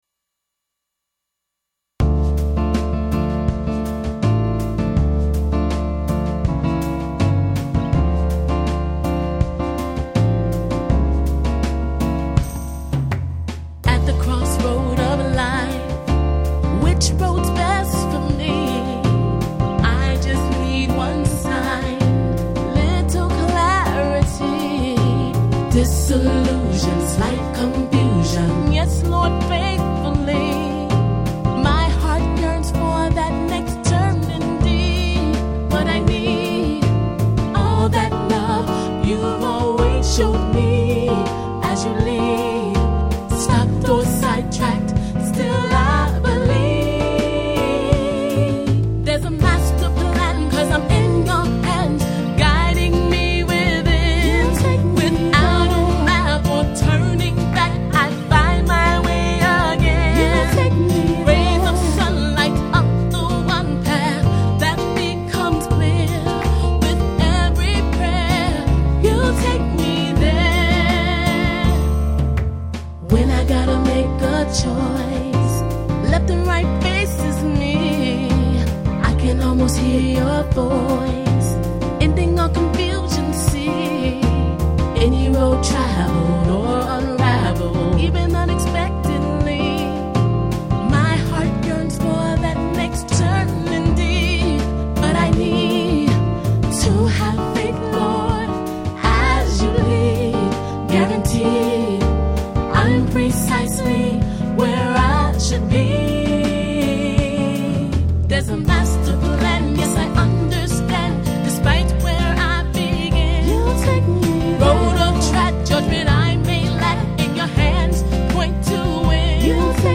Miami R&B Singer 1
Miami-RB-Singer-1-Youll-Take-Me-There.mp3